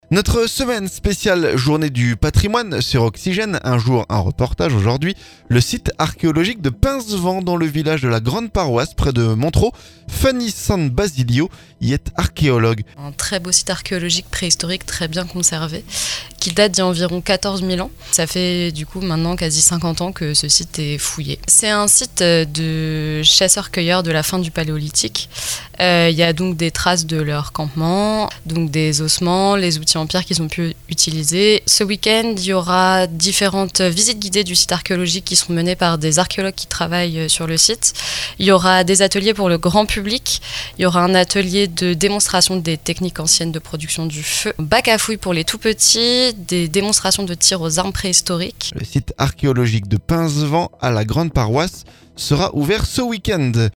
Un jour, un reportage. Ce mercredi, le site archéologique de Pincevent, dans le village de La-Grande-Paroisse près de Montereau.